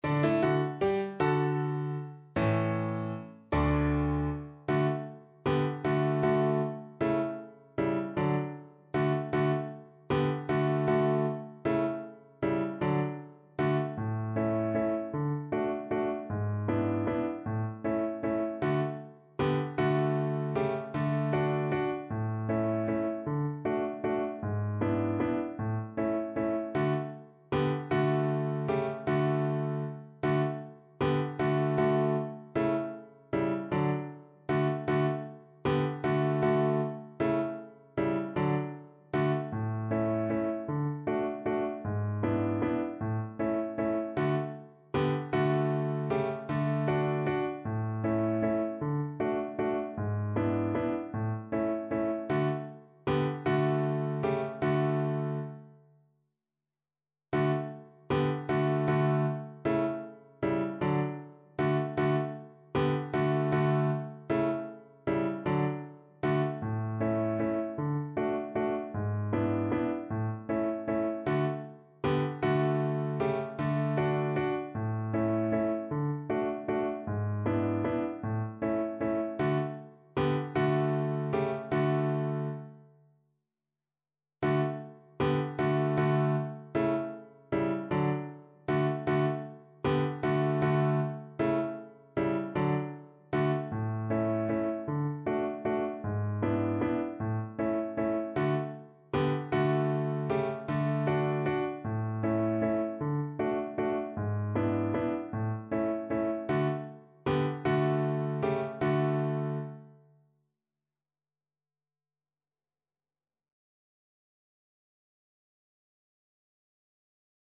kolęda: Hej, w dzień narodzenia (na klarnet i fortepian)
Symulacja akompaniamentu